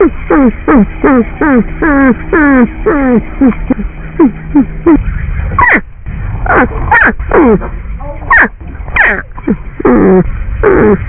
Porcupine Site: Southwest Wildlife Rehabilitation & Educational Foundation Format: WAV - 0.061MB Description: Porcupine If necessary, please download latest versions of QuickTime , RealOne Player , or Windows Media .
porc.wav